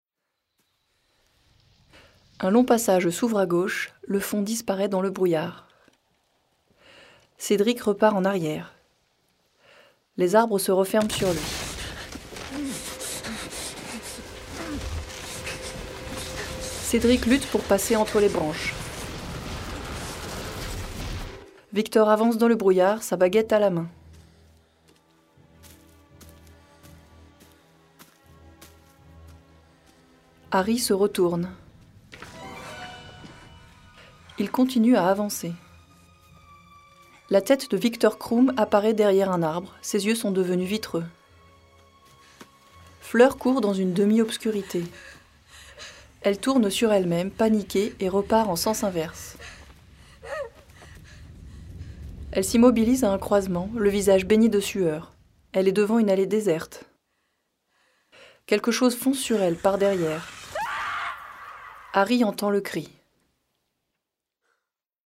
Audio-description « Harry Potter »
Des voix-off